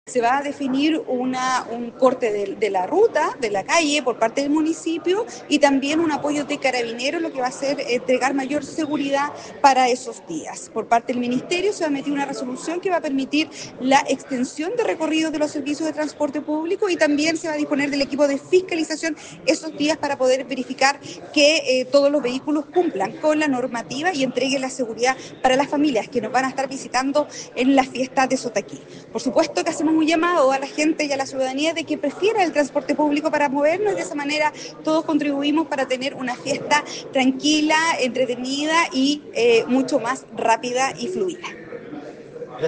En esta línea la SEREMI Alejandra Maureria indicó entre otras cosas, el reforzamiento de fiscalizadores de transportes para poder entregar mayor seguridad a los asistentes
Alejandra-Maureira-SEREMI-de-Transportes.mp3